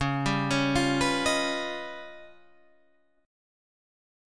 C# 9th Flat 5th